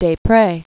(dā-prā), Josquin